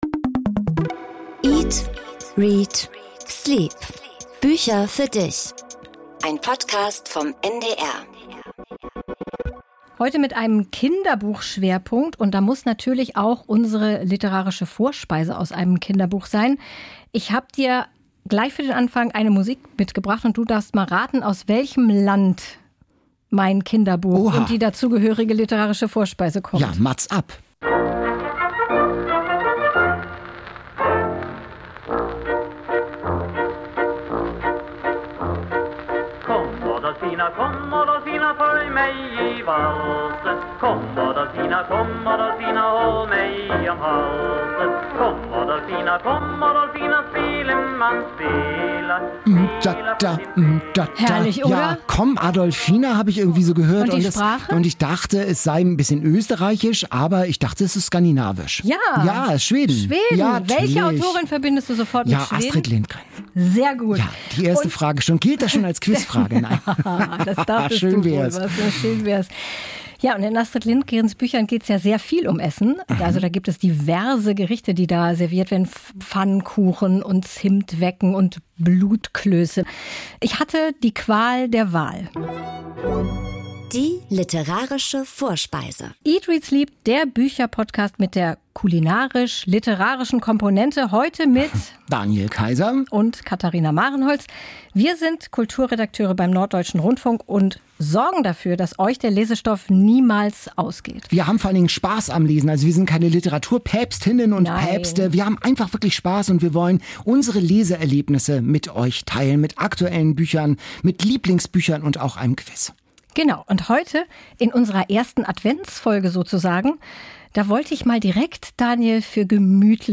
00:32:40 Interview mit Ursula Poznanski